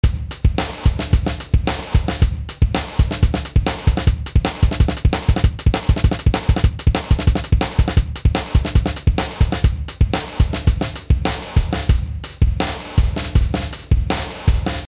テンポ・ウィンドウを開き、適当に テンポを変更してみました。
テンポ変化 （MP3 230KB）
まるで MIDIデータのように、テンポが変化してくれます。
drumloop_01.mp3